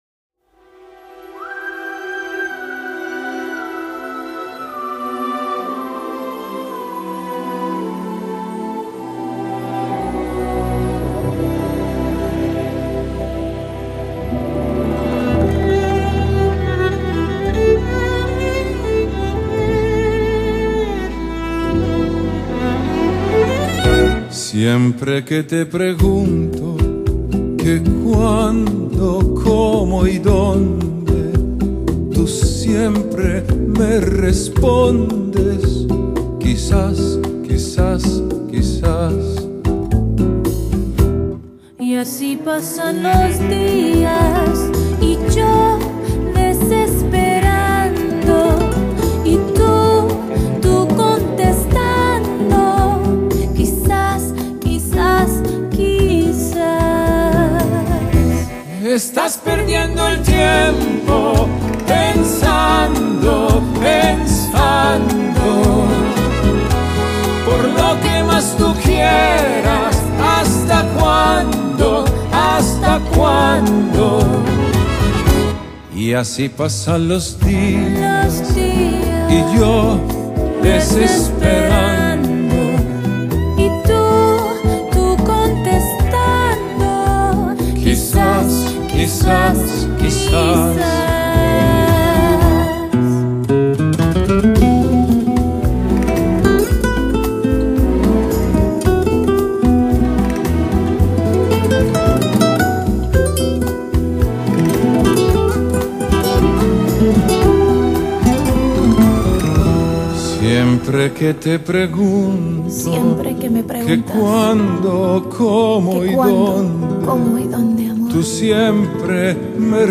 Genre: Classical, Pop